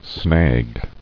[snag]